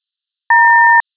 newbeep.wav